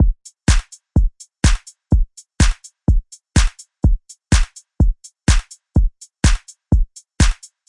描述：流动的火车穿过隧道。大量的回声， D大调的感觉。不知道我是怎么做的，可能是用了敲击和Alesis混响器的反向回声效果。
标签： 125 bpm Dance Loops Bass Loops 1.29 MB wav Key : Unknown
声道立体声